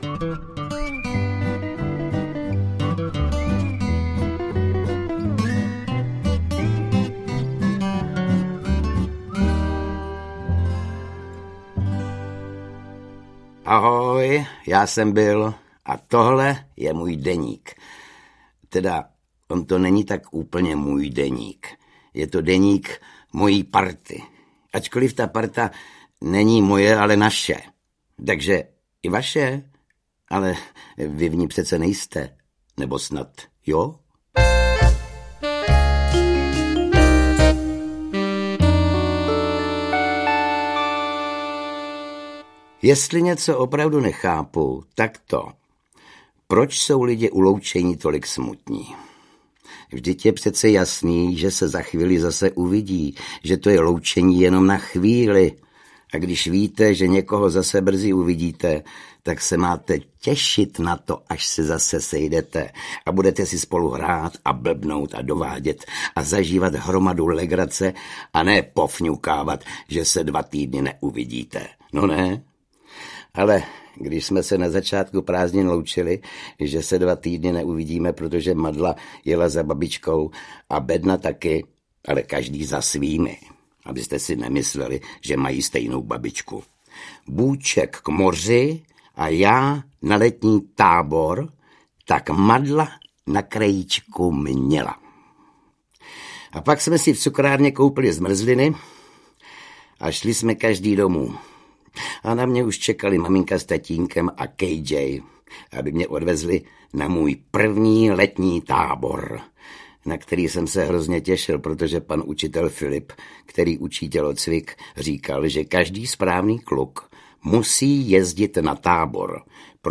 Prázdniny Billa Madlafouska audiokniha
Audiokniha Prázdniny Billa Madlafouska - obsahuje deset příběhů z úspěšné knížky pro děti v neodolatelném podání Oldřicha Kaisera.
Ukázka z knihy